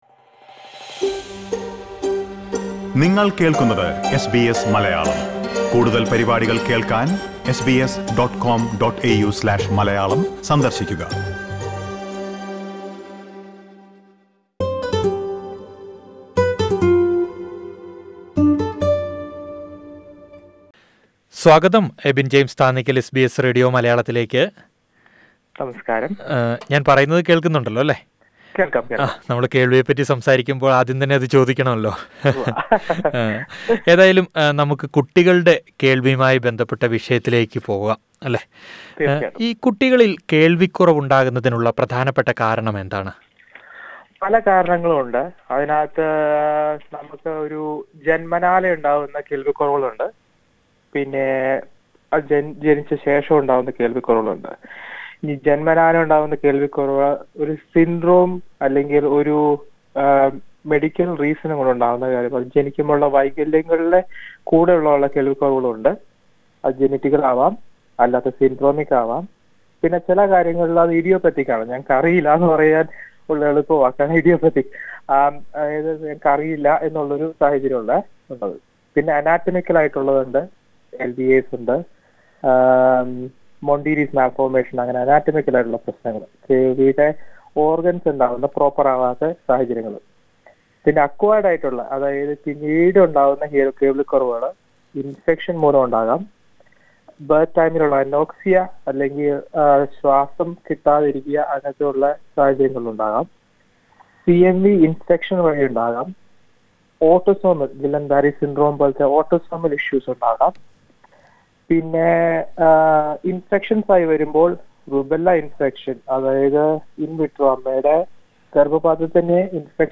Disclaimer: The information provided in this interview is general in nature.